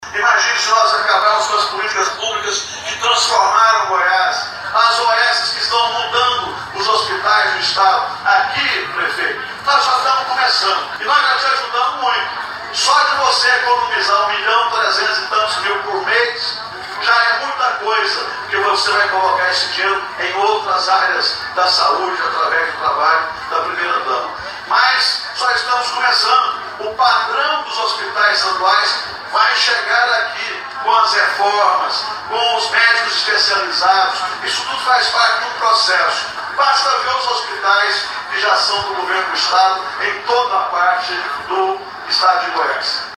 O governador discursou para autoridades e um pequeno público que o recebeu na cidade, grande parte deles funcionários públicos municipais e estaduais.